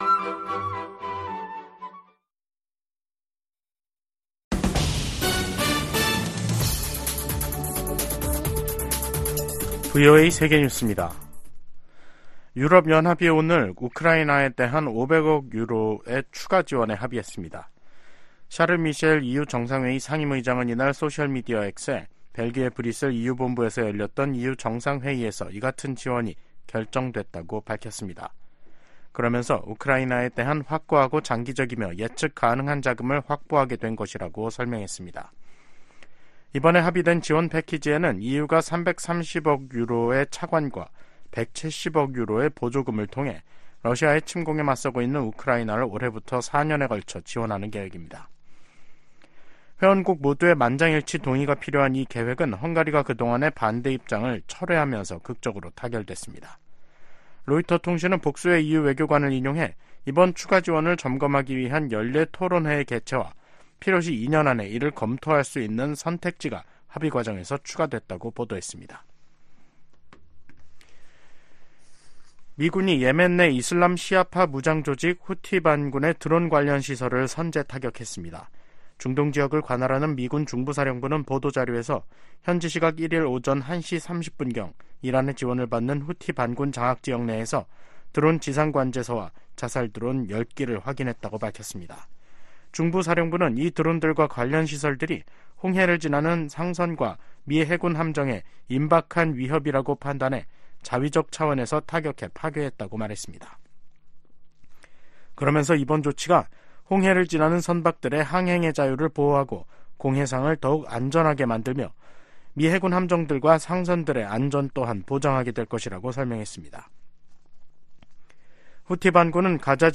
VOA 한국어 간판 뉴스 프로그램 '뉴스 투데이', 2024년 2월 1일 2부 방송입니다. 한국 총선을 겨냥한 북한의 도발 가능성에 한반도 긴장이 고조되고 있습니다. 미 국무부는 최근 중국 외교부 대표단의 방북 직후 북한이 순항미사일을 발사한 점을 지적하며, 도발을 막는데 중국의 역할이 필요하다고 강조했습니다. 미한일 협력이 북한·중국 대응을 넘어 세계 현안을 다루는 협력체로 성장하고 있다고 백악관 국가안보보좌관이 말했습니다.